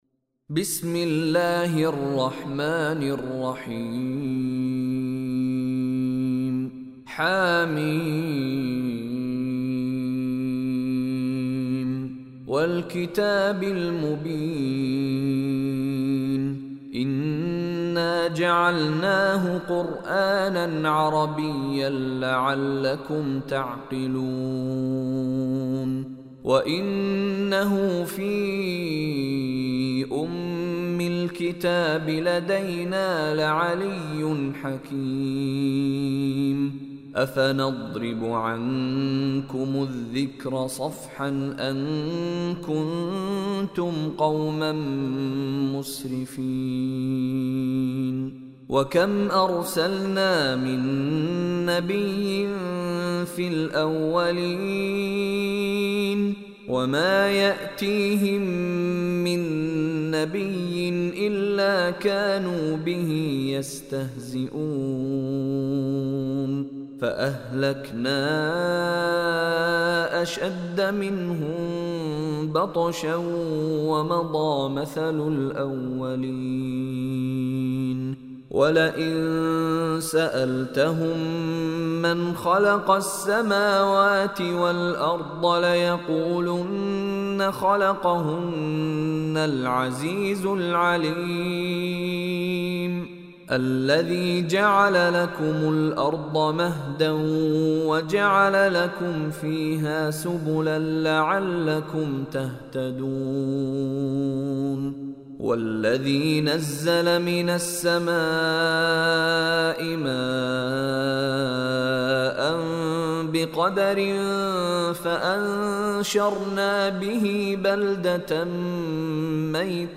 Surah Az-Zukhruf Recitation by Mishary Rashid
Surah Az-Zukhruf is 43rd is chapter / surah of Holy Quran. Listen online and download beautiful Quran recitation / tilawat of Surah Az-Zukhruf in the voice of Sheikh Mishary Rashid Alafasy.